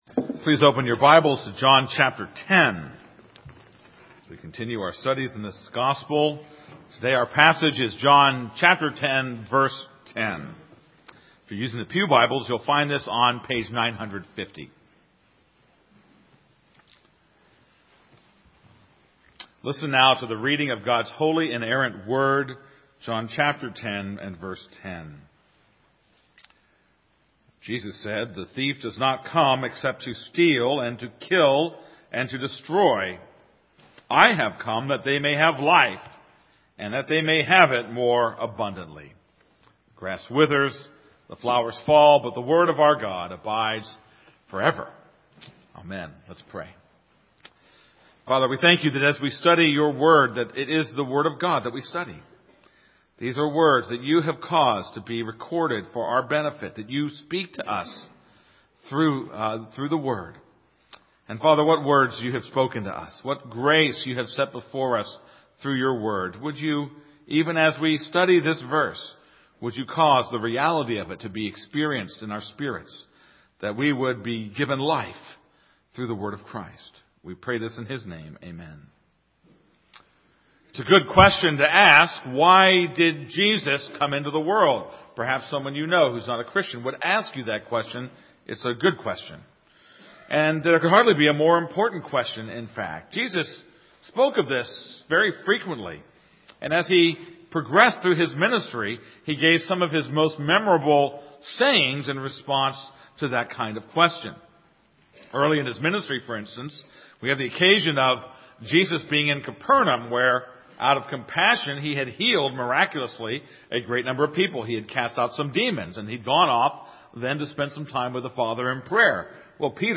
This is a sermon on John 10:10.